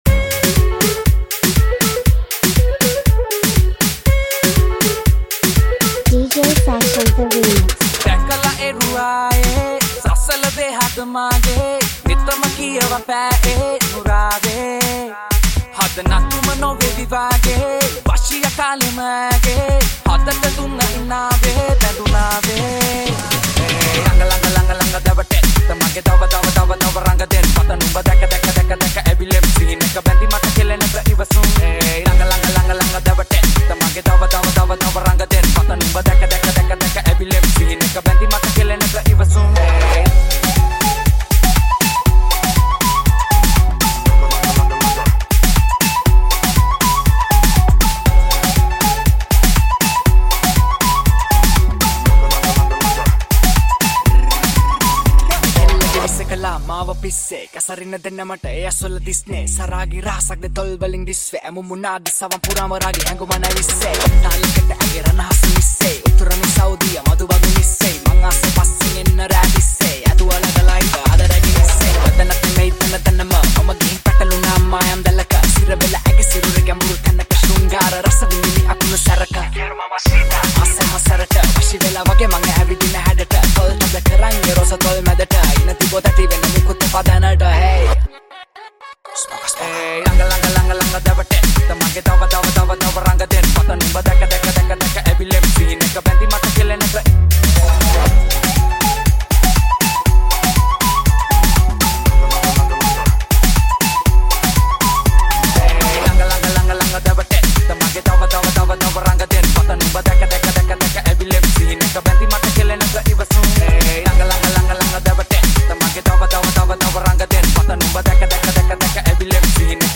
Party Remix